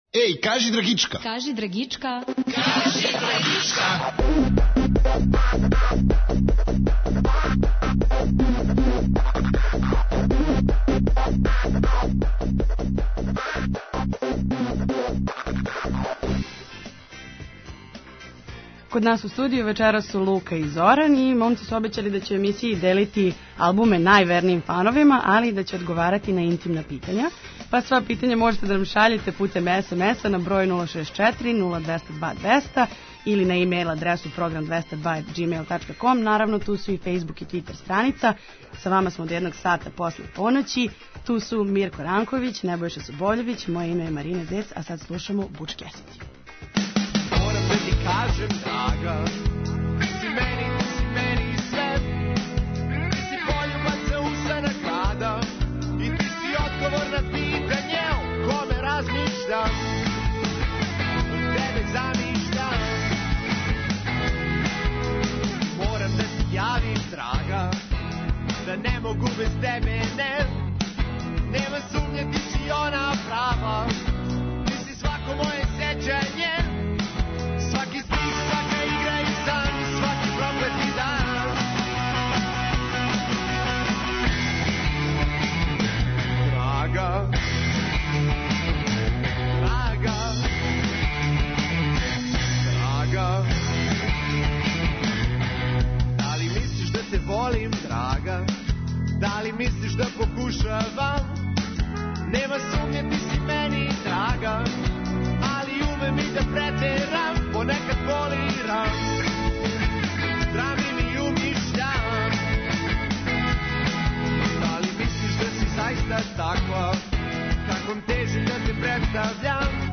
Вечерас слушамо музику по избору момака из бенда Буч Кесиди.